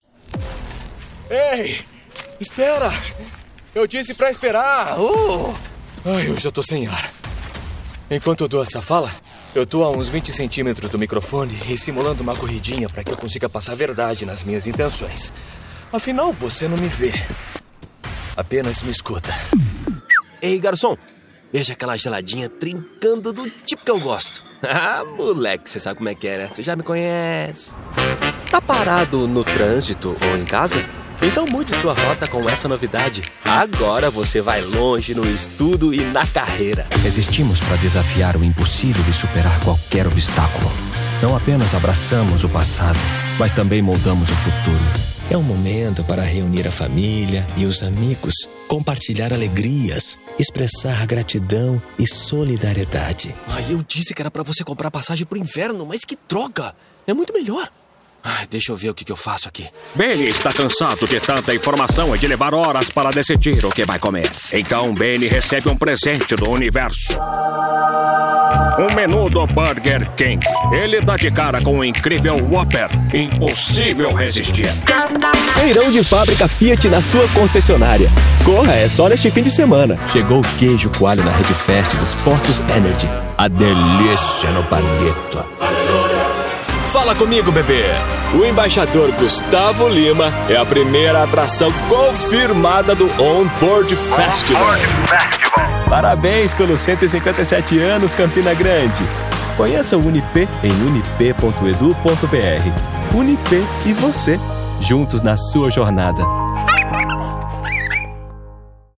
I’m a Brazilian voice actor with a natural accent.
Dynamic Neutral Friendly